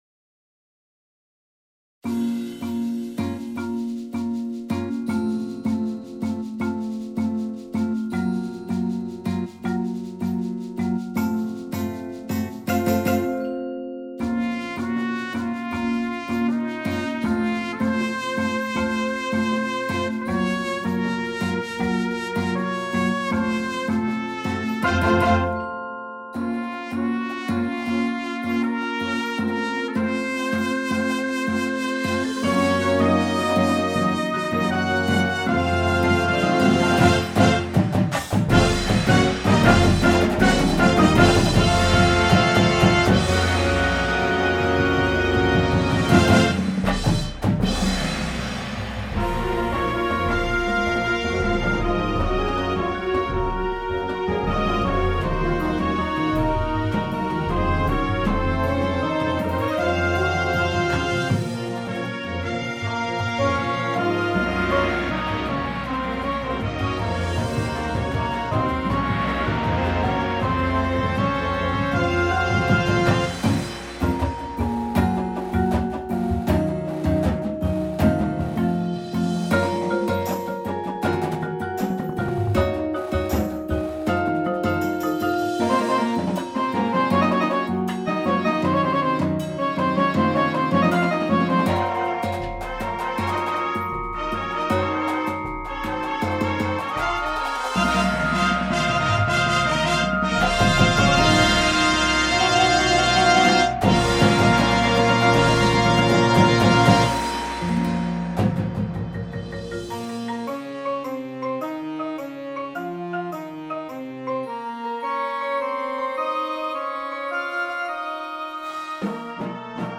Instrumentation:
• Flute
• Alto Sax
• Tuba
• Snare Drum
• Bass Drums